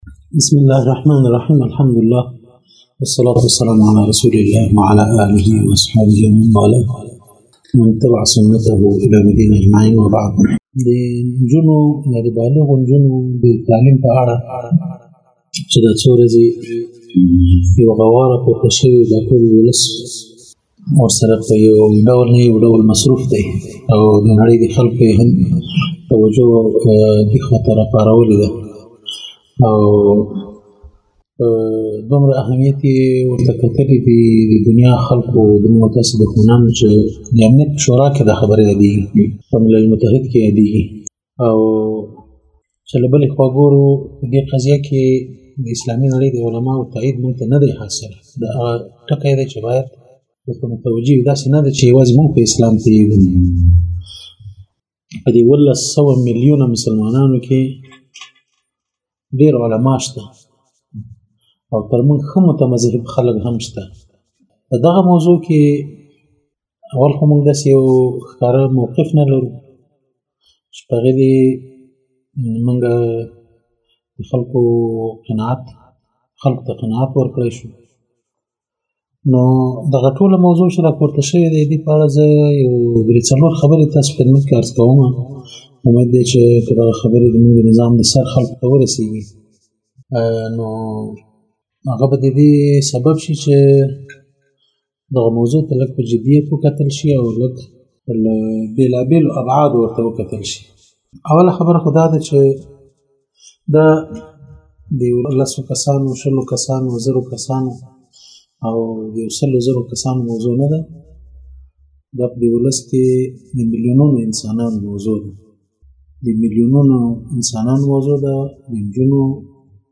🔊بیان